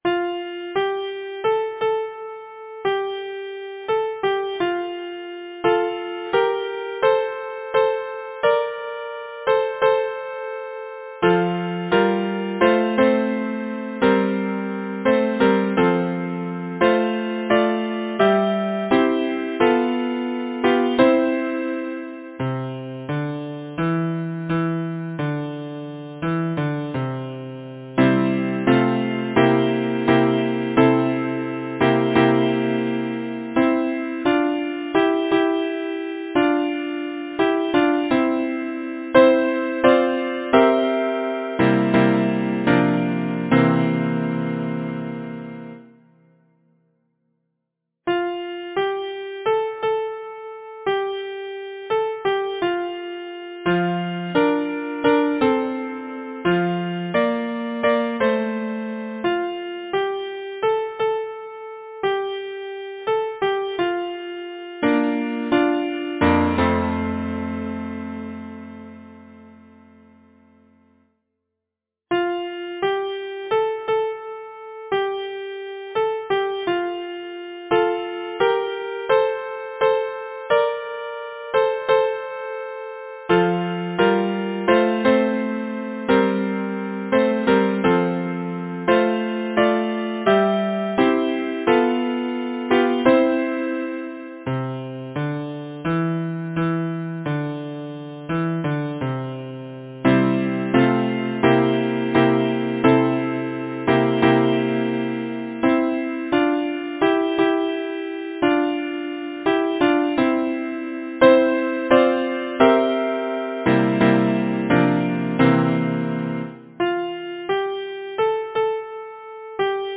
Title: We are waiting Composer: Frederic Woodman Root Lyricist: George Cooper Number of voices: 4vv Voicing: SATB Genre: Secular, Partsong
Language: English Instruments: A cappella